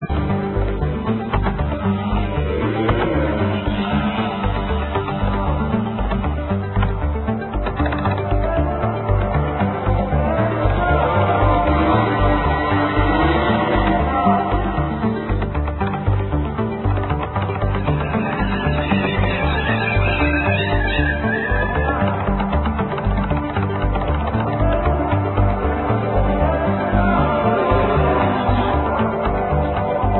3 campionamenti di canti di nativi americani